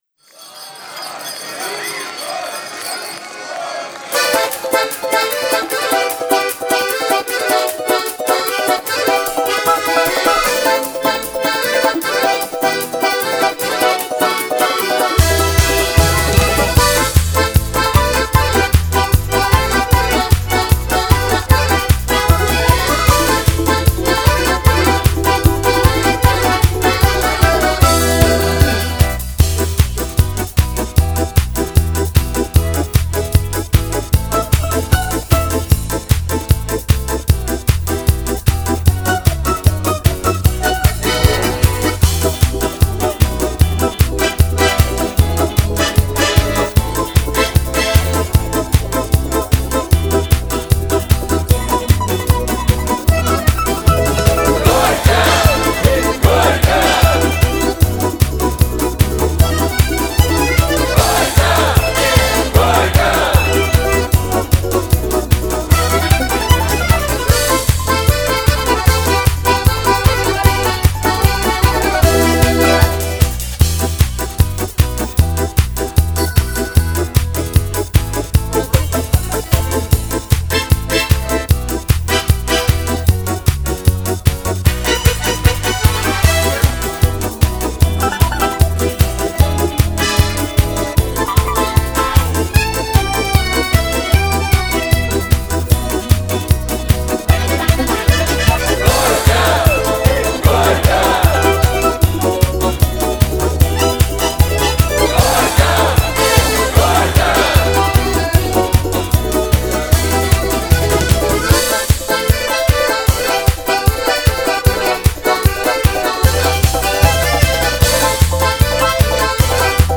Свадебные